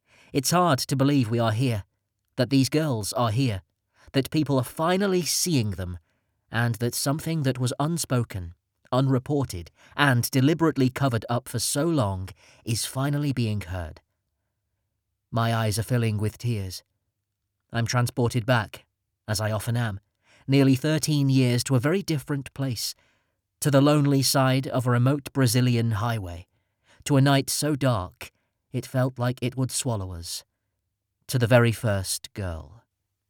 Narrator
Before the Night Comes (Non fiction).mp3